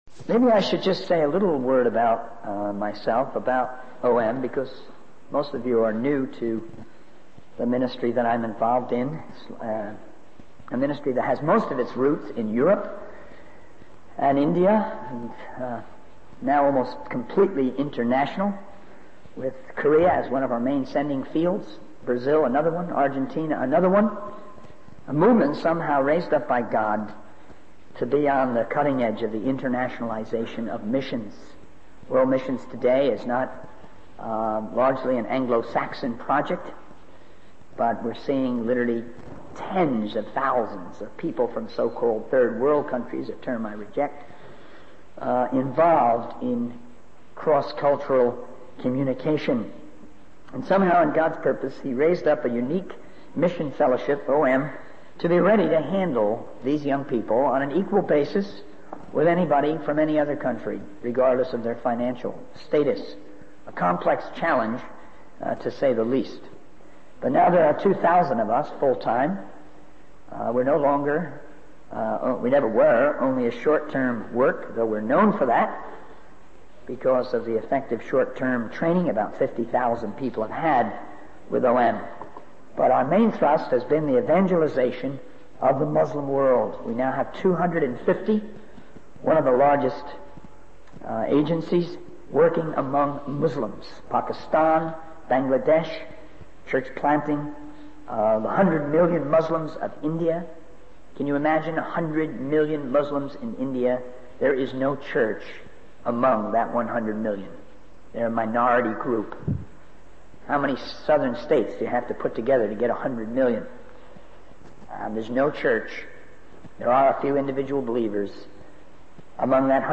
In this sermon, the speaker emphasizes the importance of teaching and understanding the principles of support raising, winning friends, mobilizing God's people, and finding resources for world evangelism. The lack of knowledge and willingness to sacrifice in the church is a concern for the speaker. He encourages the audience to consider their lifestyle, mentality, and approach to money in relation to the unreached people and make decisions that will bring about a great missionary thrust.